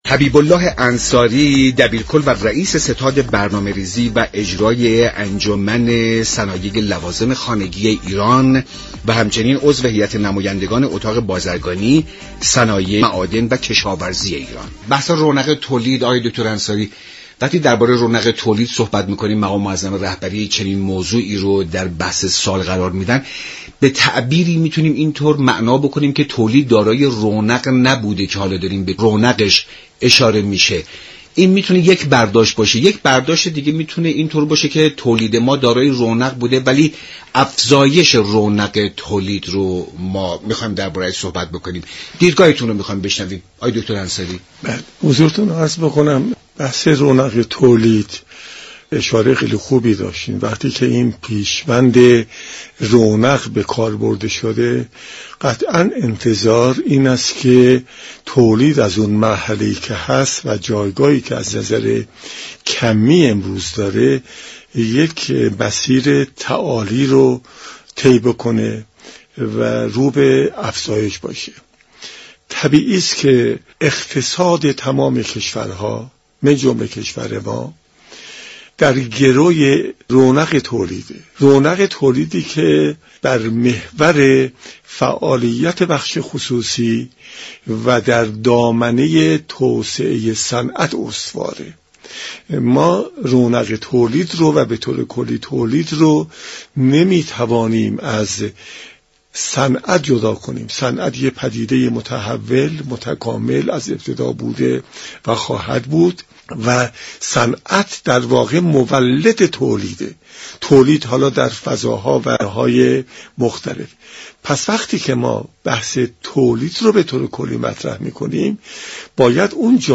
برنامه میزبان گروه دانش و اقتصاد روزسه شنبه ساعت 11:55 از رادیو ایران پخش می شود. این گفت و گو را در ادامه باهم می شنویم.